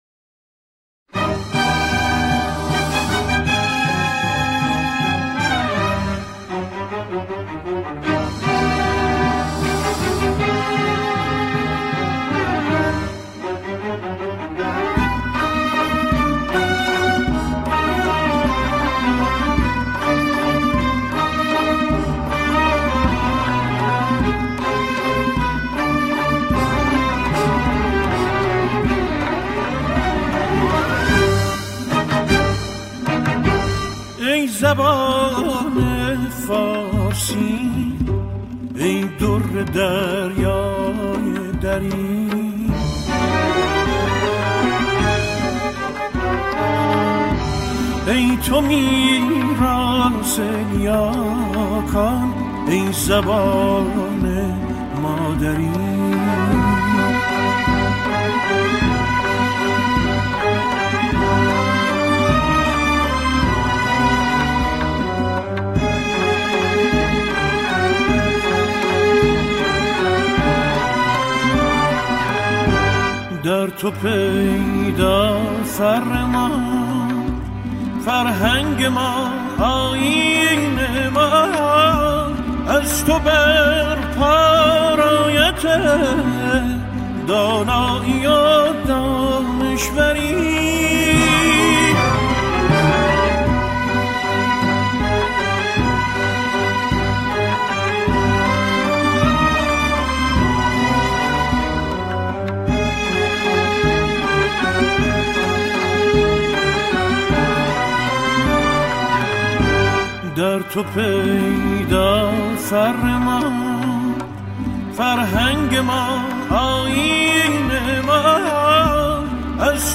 موسیقی فاخر پارسی